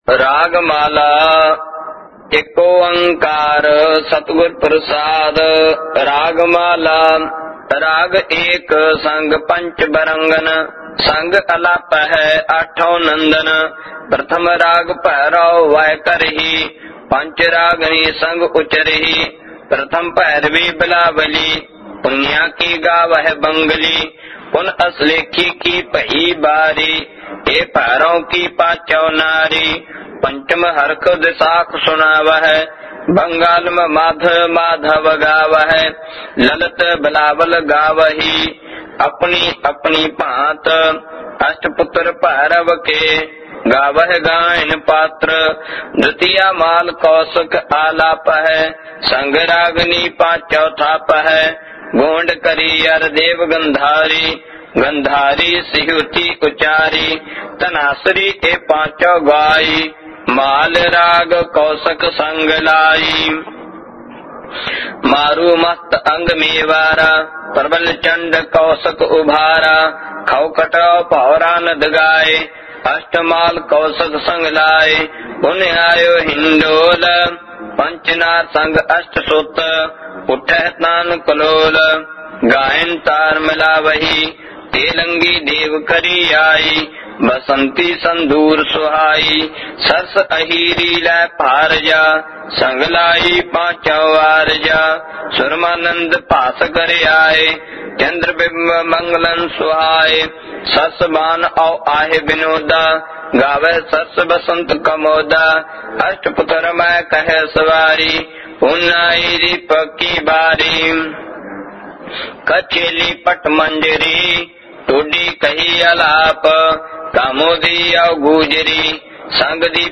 Genre: -Gurbani Ucharan